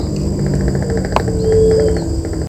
Carpinterito Común (Picumnus cirratus)
Nombre en inglés: White-barred Piculet
Condición: Silvestre
Certeza: Fotografiada, Vocalización Grabada
Carpinterito-barrado_1.mp3